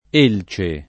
%l©e] (antiq. elice [%li©e]) s. f. — voce lett. per «leccio»: elce antiqua et negra [%l©e ant&kUa e nn%gra] (Petrarca); di cima un’elice [di ©&ma un %li©e] (Sannazzaro); l’elci frondose [